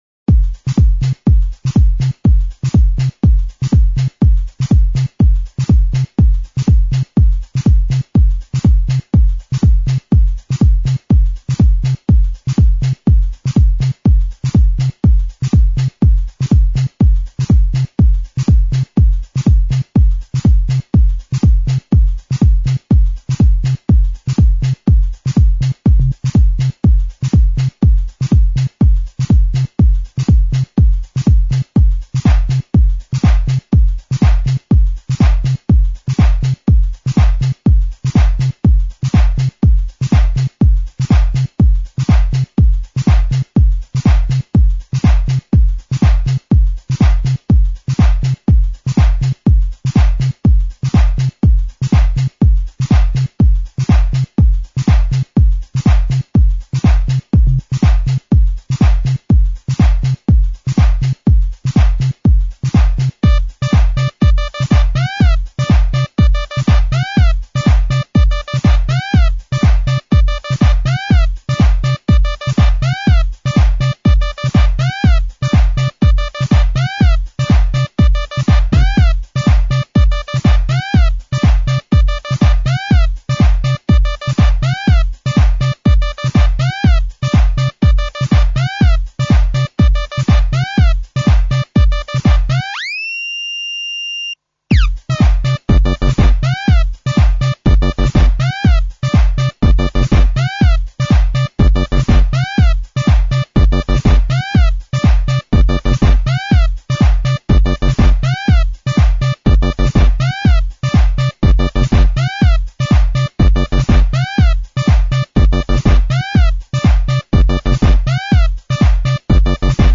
/32kbps) Описание: Энергичная музычка...